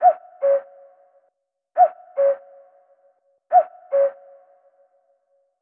klingelton-kuckuck.mp3